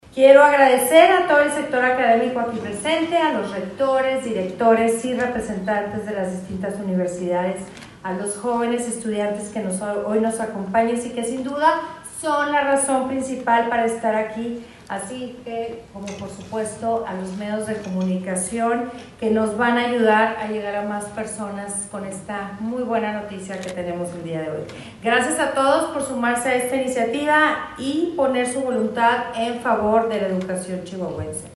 AUDIO: MARÍA EUGENIA CAMPOS, GOBERNADORA DEL ESTADO DE CHIHUAHUA